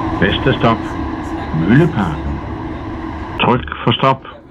Højttalerudkald Metro og Letbane
Jørgen Leth udkald.